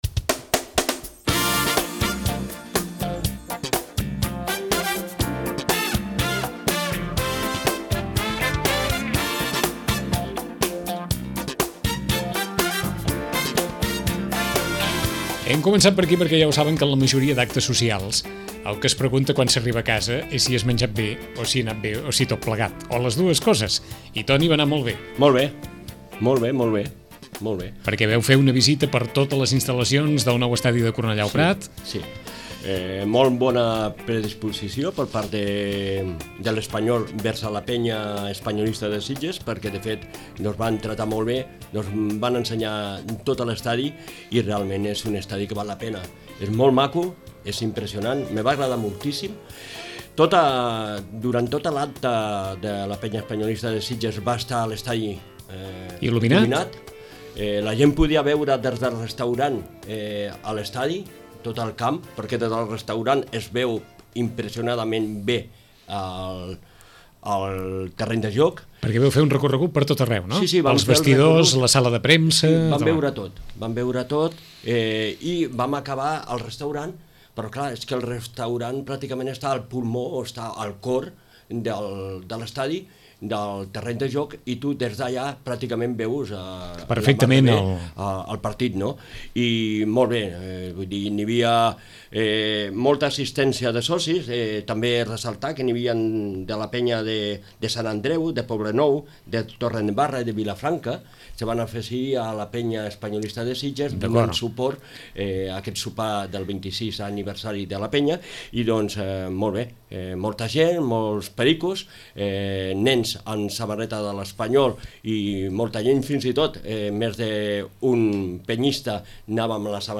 us apropem al sopar de celebració del 26è aniversari de la Penya Espanyolista de Sitges, que va tenir lloc al restaurant de l’estadi de Cornellà-El Prat.